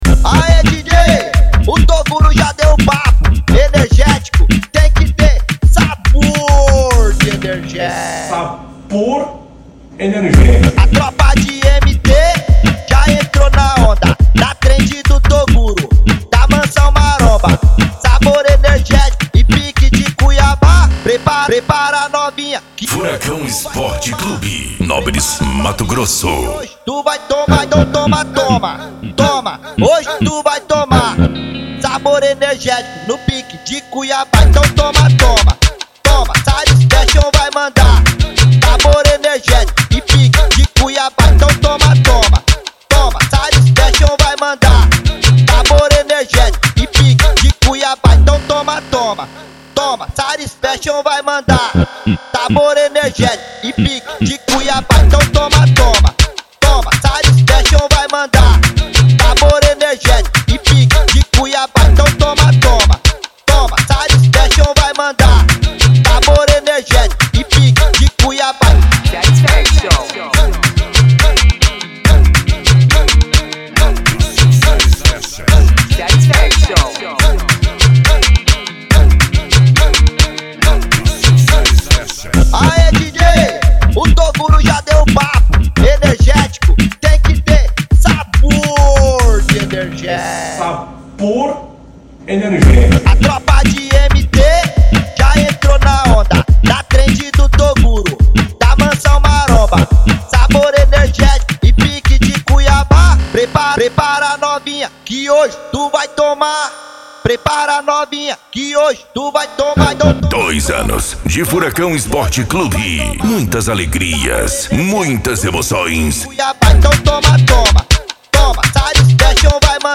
Funk
SERTANEJO
Sertanejo Raiz